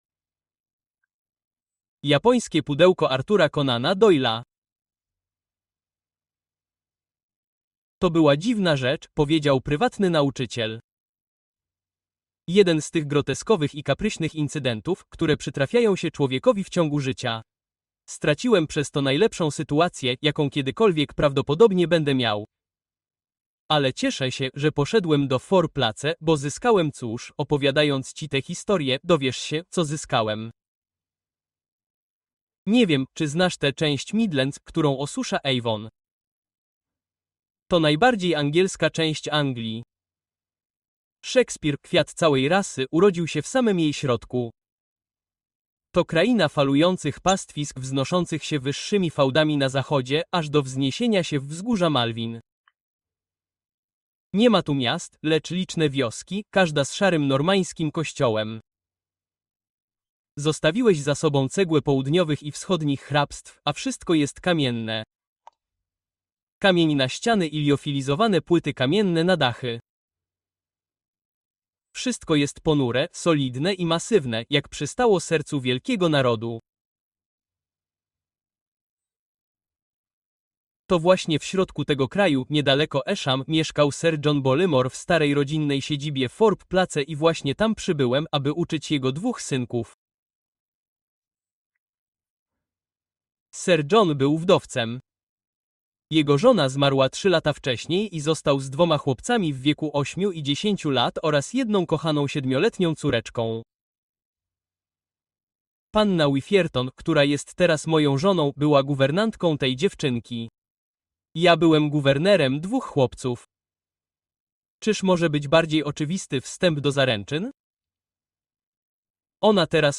The Haunted Manor: Chilling Mysteries You Can’t Miss (Audiobook)